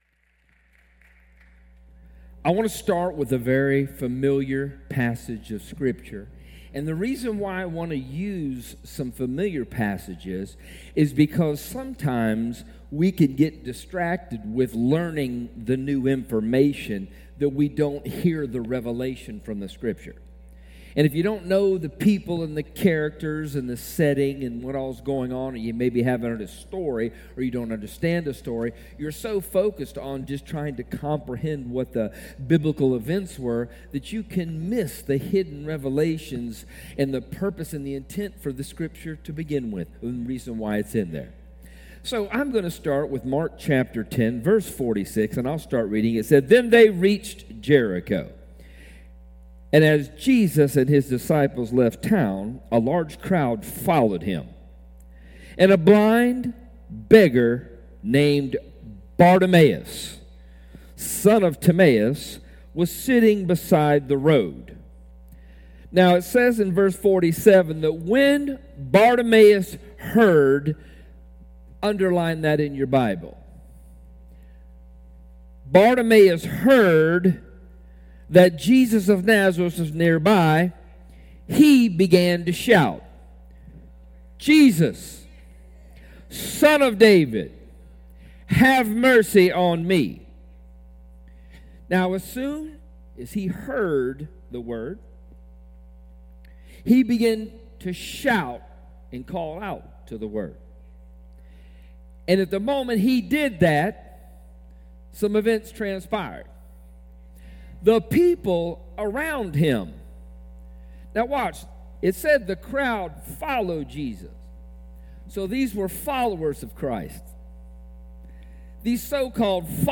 Sunday and Wednesday sermons from Glory To Him Church in Ozark, AL.